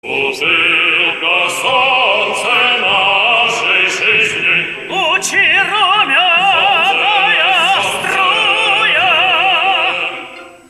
Застольная песенка монахов сменилась пением Псалмов: ведь явились клиенты, и настоятель вмиг обвенчал влюблённых.